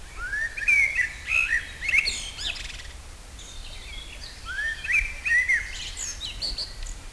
ever-jubilant Merlo on antenna
delightful singer across the street was a "Merlo" (like the wine without the 't') - it's got to be the equivalent of an English "merl" or thrush (Turdus merula).